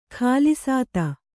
♪ khālisāt